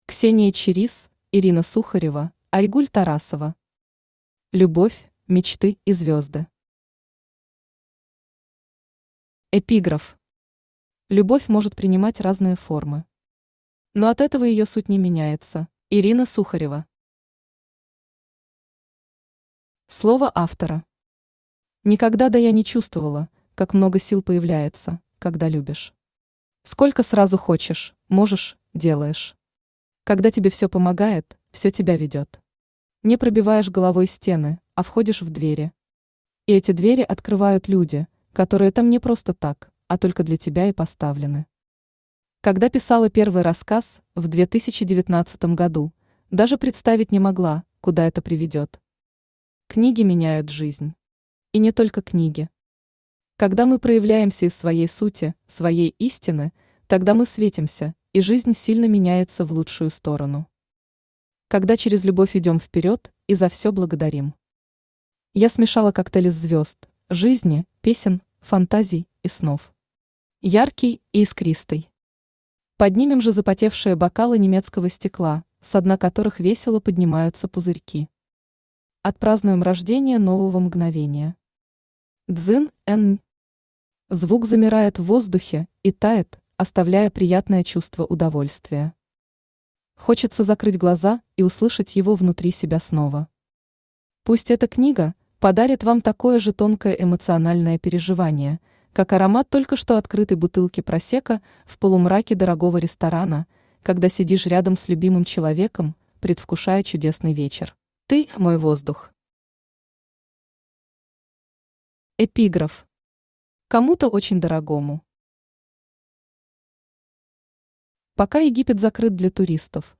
Аудиокнига Любовь, мечты и звёзды